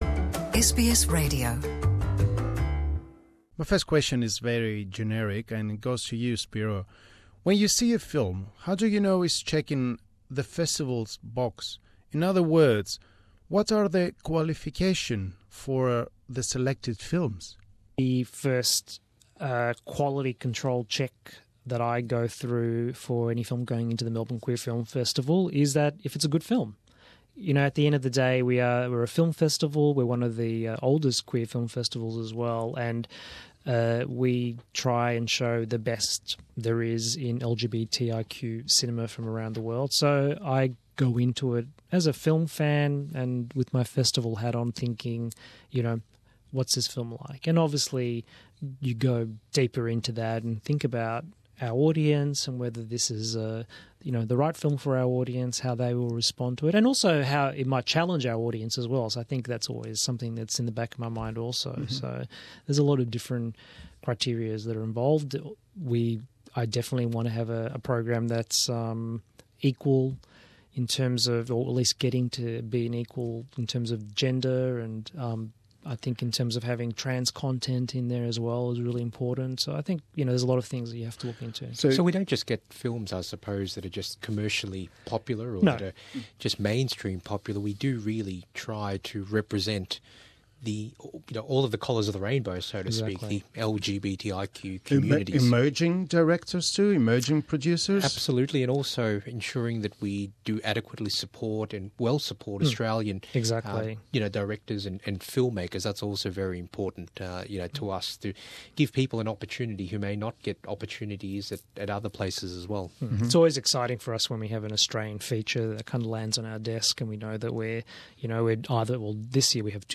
at the SBS studios